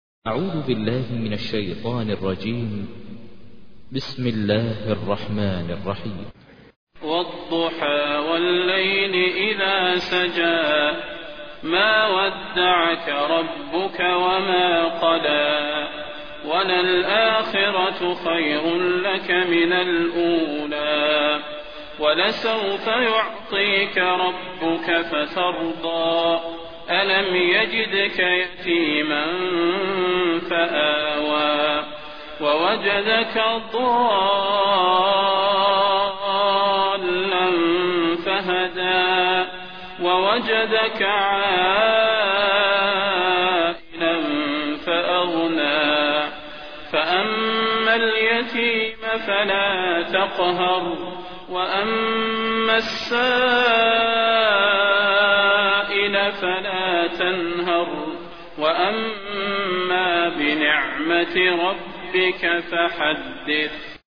تحميل : 93. سورة الضحى / القارئ ماهر المعيقلي / القرآن الكريم / موقع يا حسين